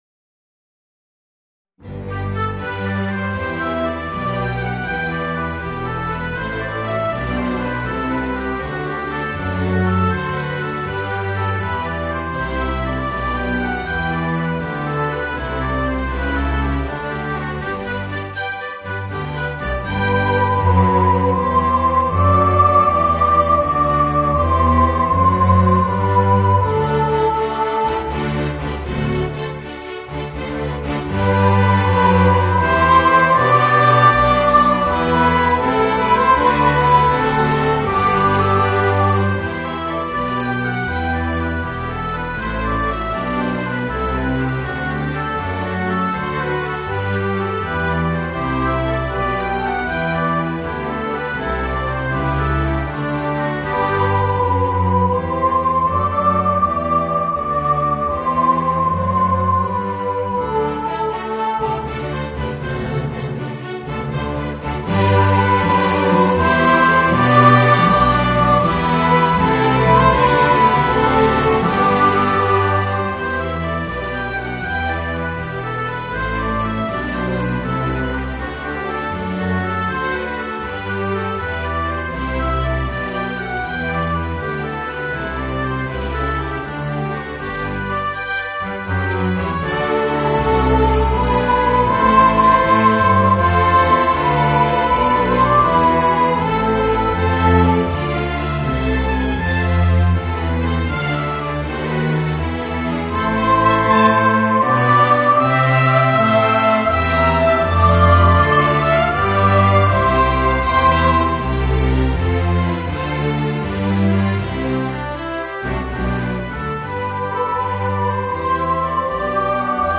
Voicing: Soprano, Oboe and Orchestra